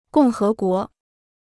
共和国 (gòng hé guó): republic.